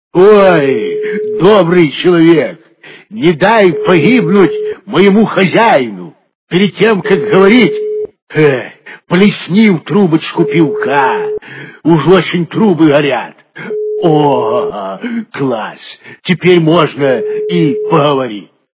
Голос пародия - Пародия на Папанова Звук Звуки Голос пародія - Пародия на Папанова
» Звуки » Люди фразы » Голос пародия - Пародия на Папанова
При прослушивании Голос пародия - Пародия на Папанова качество понижено и присутствуют гудки.